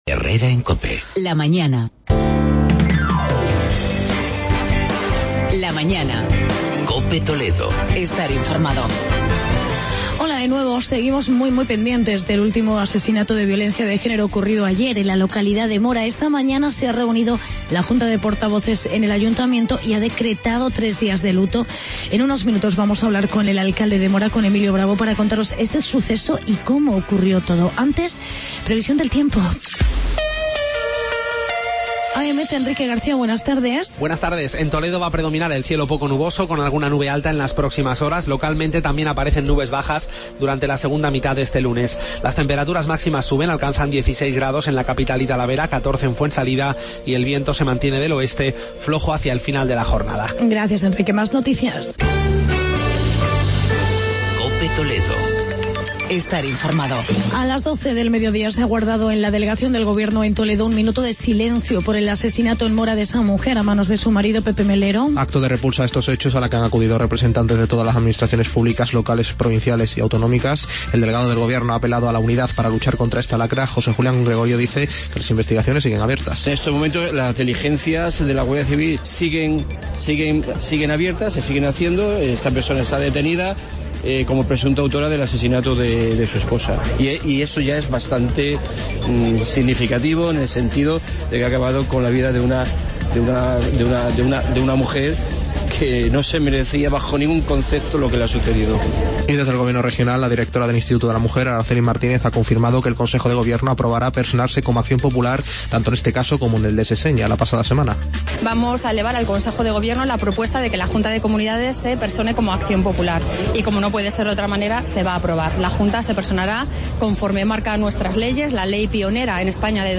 Actualidad y entrevista con Emilio Bravo, alcalde de Mora.